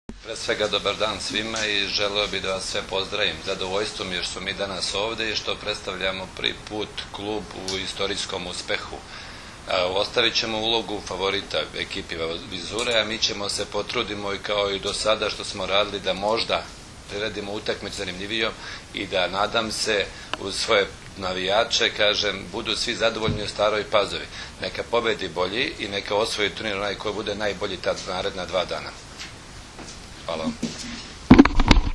U prostorijama Odbojkaškog saveza Srbije danas je održana konferencija za novinare povodom Finalnog turnira 48. Kupa Srbije u konkurenciji odbojkašica, koji će se u subotu i nedelju odigrati u dvorani “Park” u Staroj Pazovi.